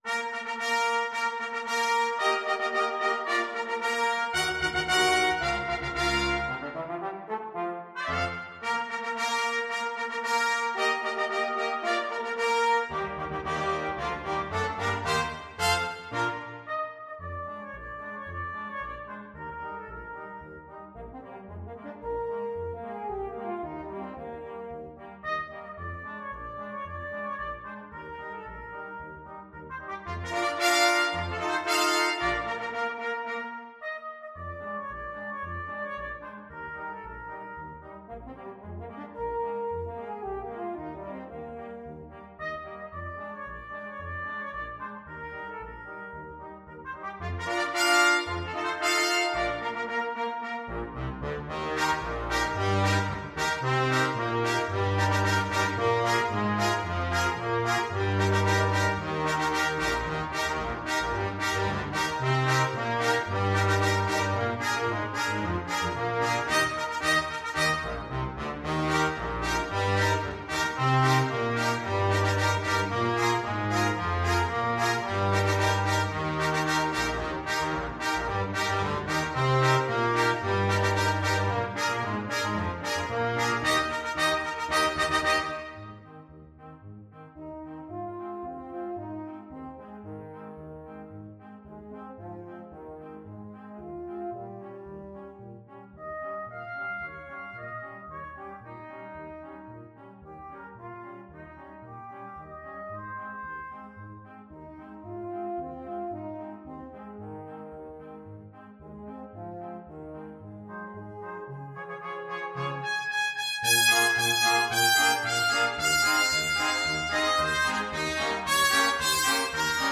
Trumpet 1Trumpet 2French HornTromboneTuba
2/4 (View more 2/4 Music)
~ = 112 Introduction
Classical (View more Classical Brass Quintet Music)